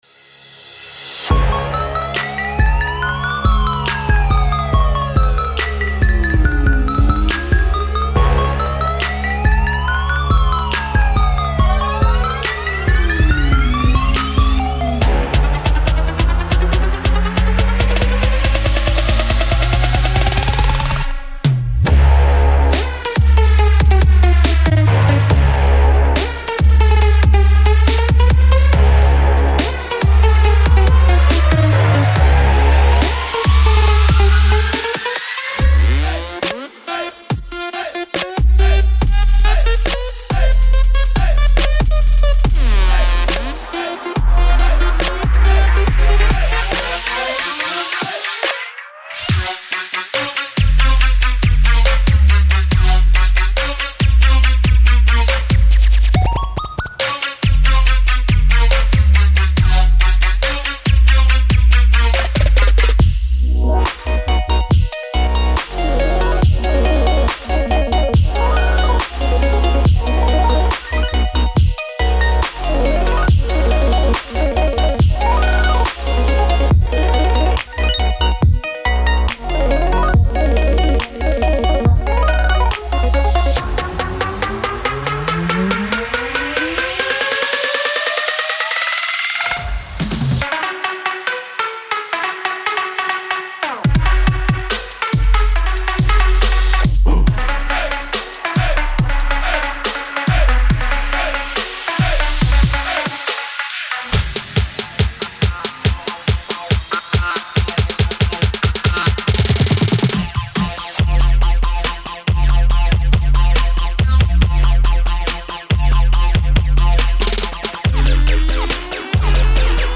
patches and presets for reveal spire vst synth
Alongside heavy hybrid trap presets, you will also find melodic tunes for the new subgenre Future Bass inspired by Flume, DJ Snake and T-Mass.
Presets in detail: 15 bass, 2 bass sequences, 3 sequences, 12 plucks, 9 FX, 18 leads, 1 pads and 3 synth sounds.
Heavy_Trap_For_Spire_Vol.1_Demo.mp3